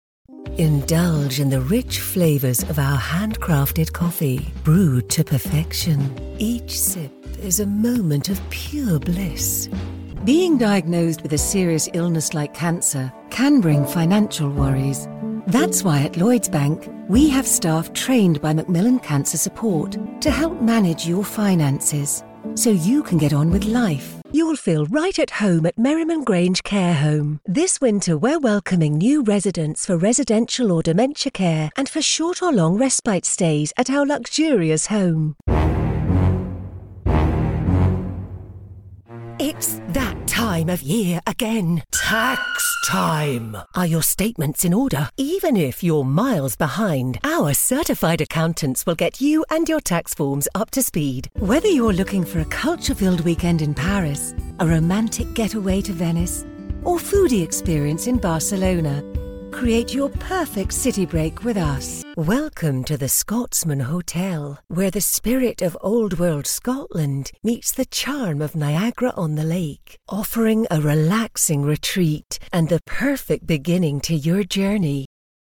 Engels (Brits)
Warm, Vriendelijk, Veelzijdig, Natuurlijk, Commercieel
Commercieel